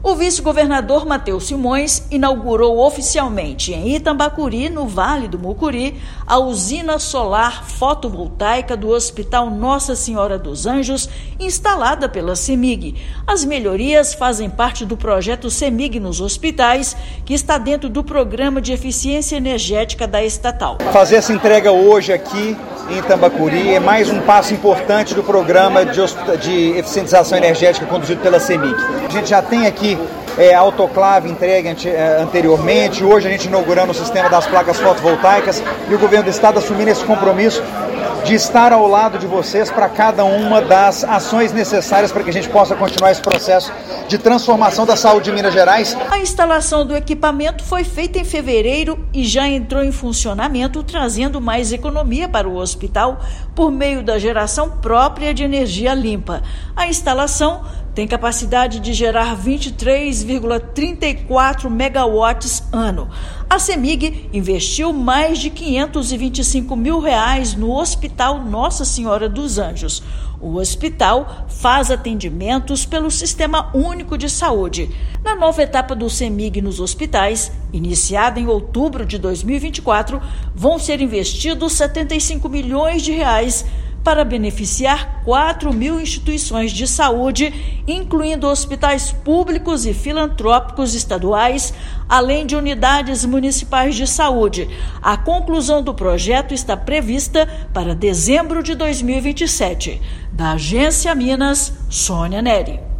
[RÁDIO] Governo de Minas inaugura usina fotovoltaica no Hospital Nossa Senhora dos Anjos, em Itambacuri
CEMIG_NOS_HOSPITAIS_-_VICE-GOVERNADOR.mp3